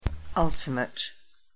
O ile łatwo zapamiętać, że U przeczytamy jak krótkie A, to już końcówka MATE kojarzy nam się z kumplem i tak próbujemy też przeczytać.
Tę końcówkę czytamy jak met, a nie mejt.
pronunciation_en_ultimate.mp3